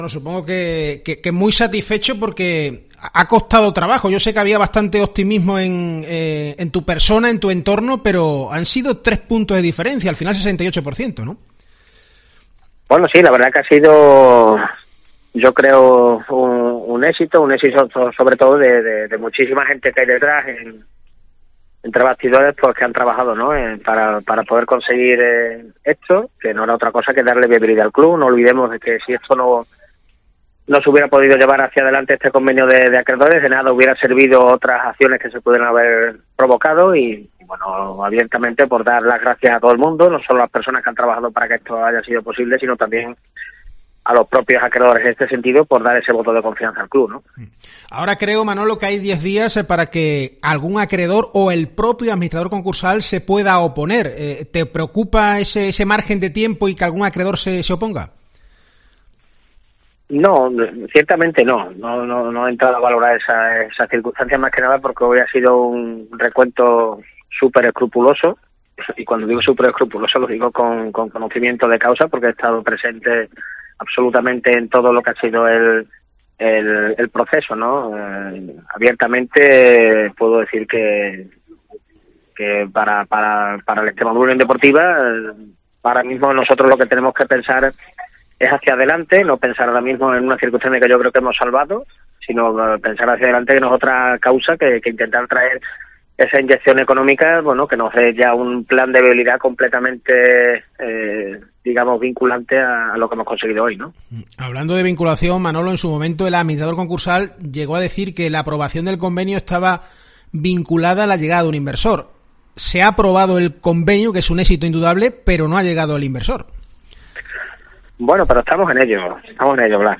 en declaraciones a COPE Almendralejo